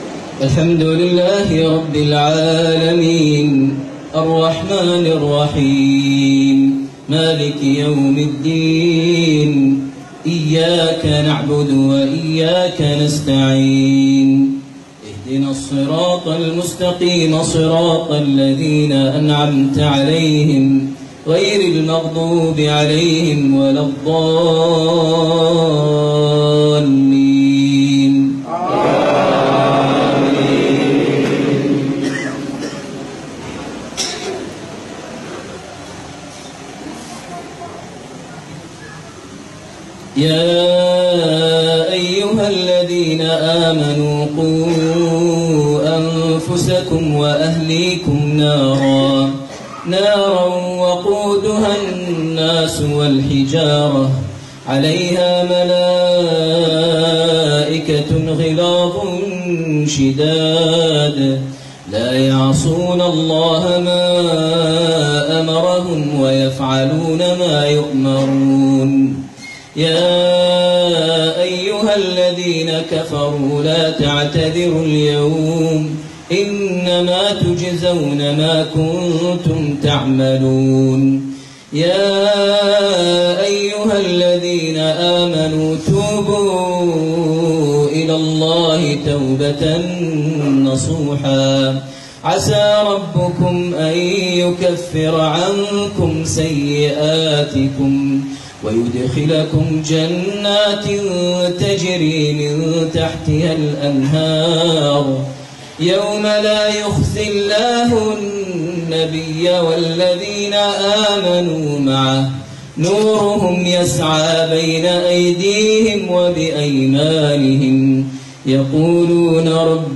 لأول مرة تعرض | تلاوة نادرة | صلاة المغرب من دولة الفلبين > 1435 هـ > الفروض - تلاوات ماهر المعيقلي